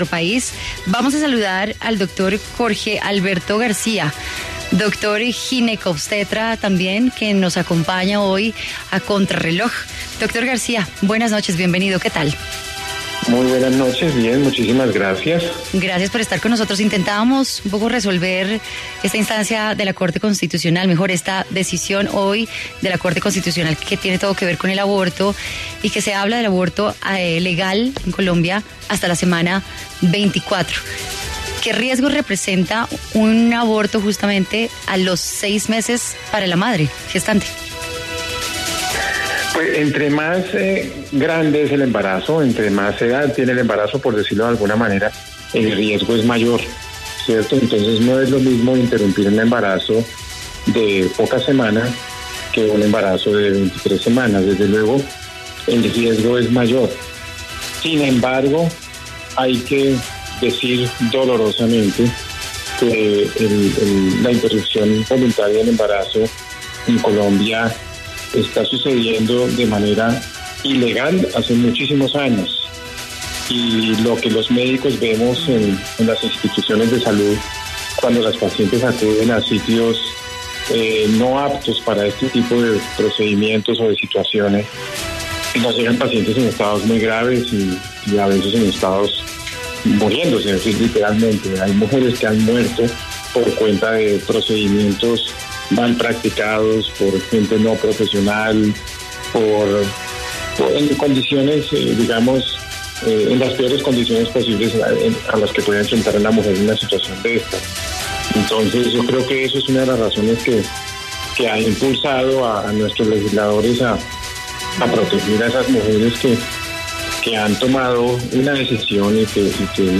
“Entre más tiempo tiene el embarazo, mayor es el riesgo”: médico ginecobstetra sobre el aborto